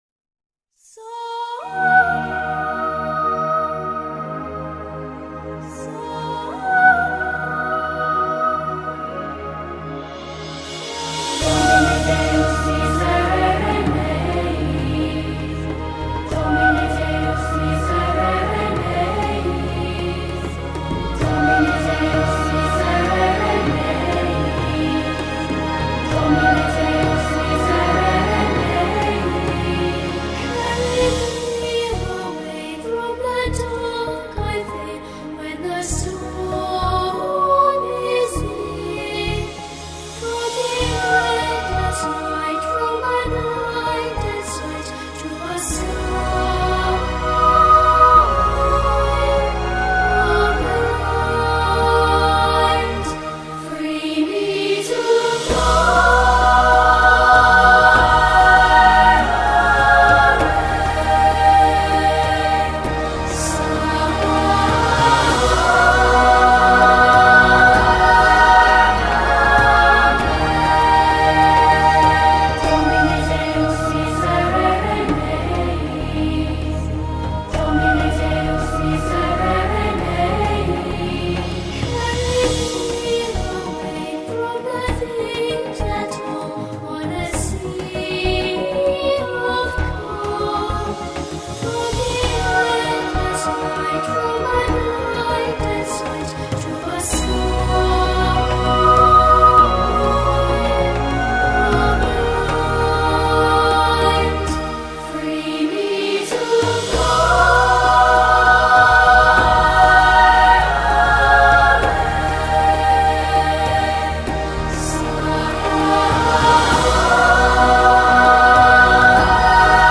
Salva_me_for_chos-_ангельские_голоса